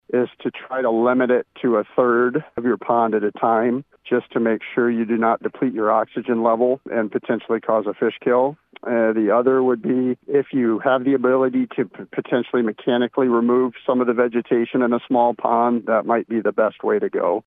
News Brief